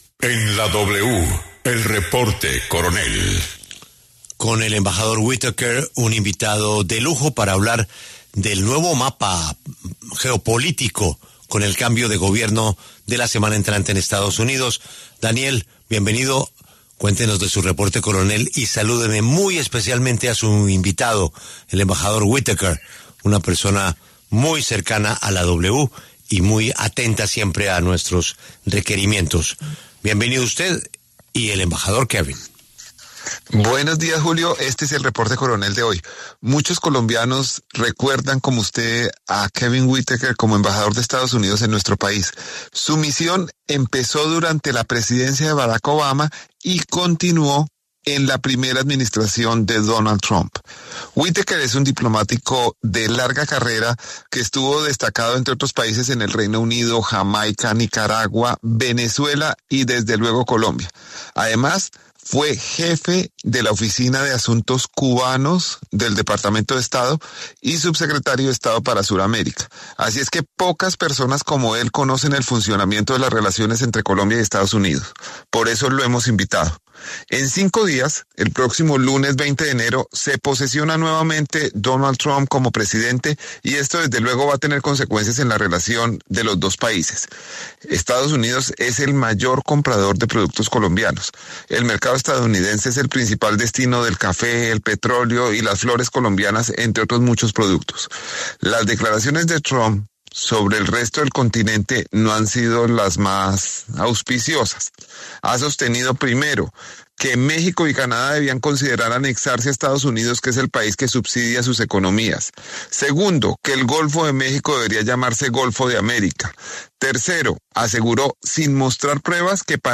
Kevin Whitaker, exembajador de Estados Unidos en Colombia, conversó con El Reporte Coronell sobre la posesión de Donald Trump como presidente y las relaciones entre ambos países.